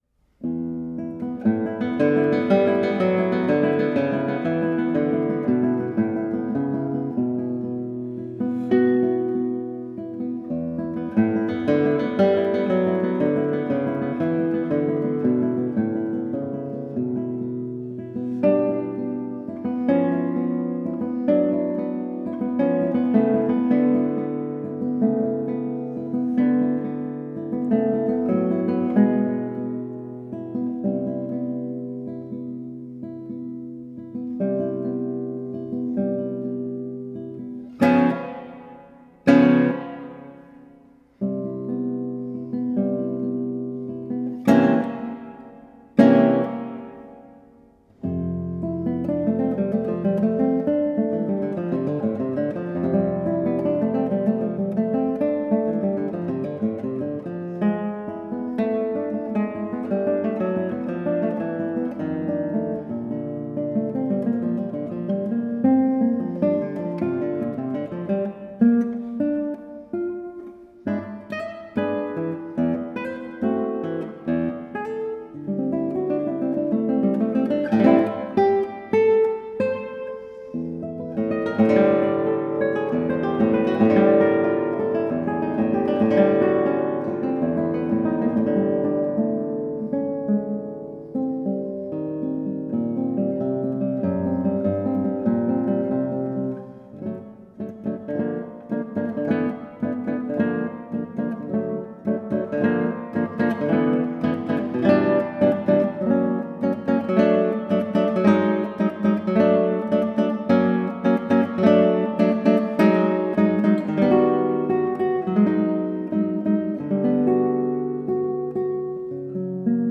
is sure and humour-filled.